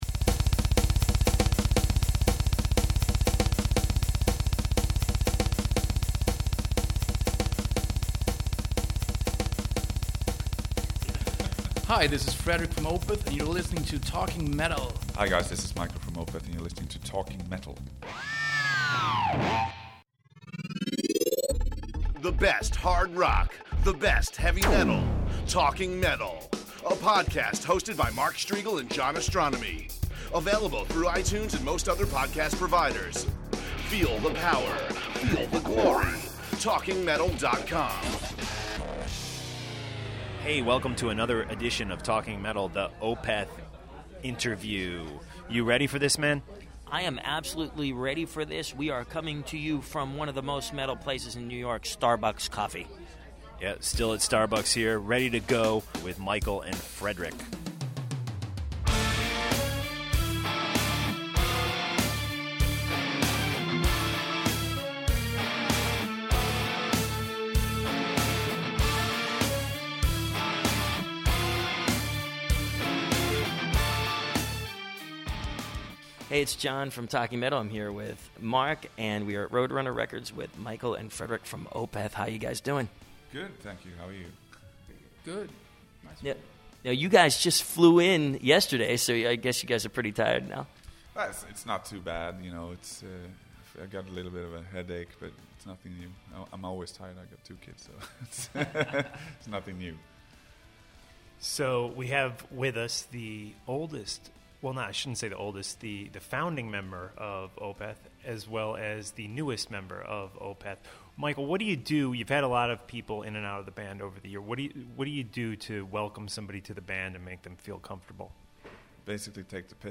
Микаэль Окерфельдт Mikael Akerfeldt | Интервью Q Мы снова в кафе Starbucks и берём интервью у Микаэля и Фредрика из Opeth.